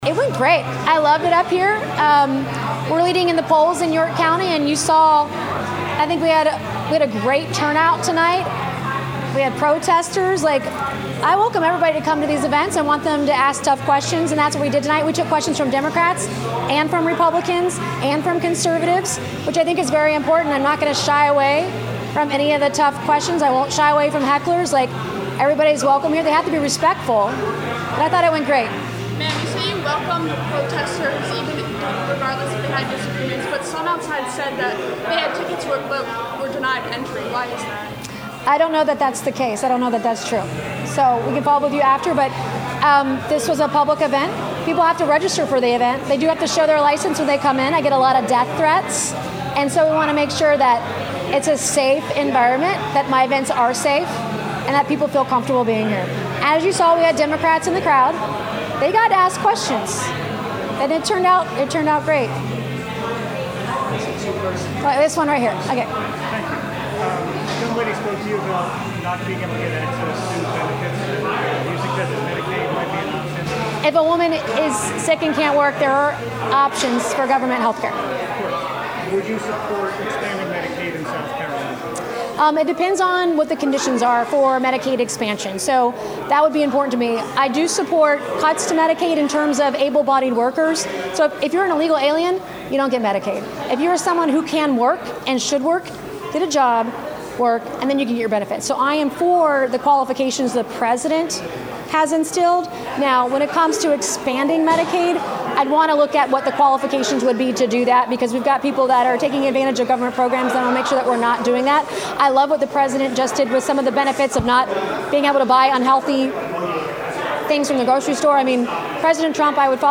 AUDIO: Nancy Mace speaks in Rock Hill during her campaign for Gov
This is the media scrum audio after the event held by Nancy Mace and her campaign. She talked policies, as well as Silfab, and praised York County for their trade schools and programs